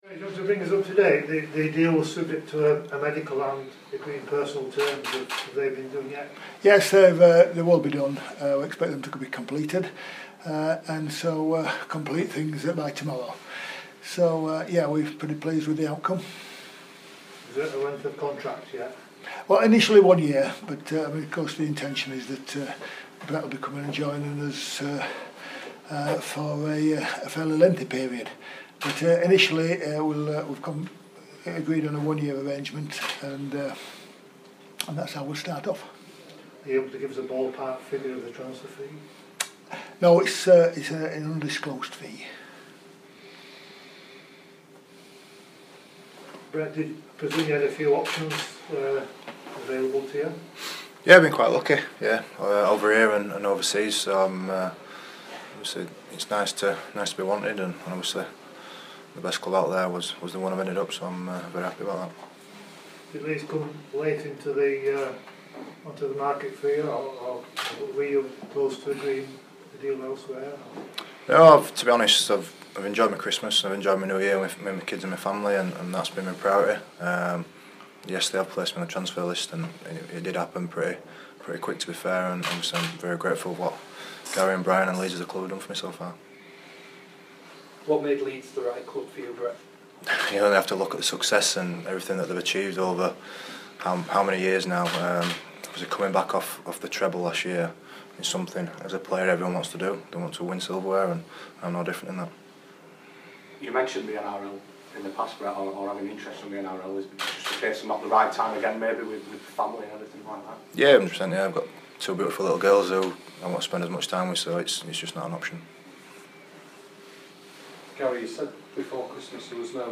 press conference in full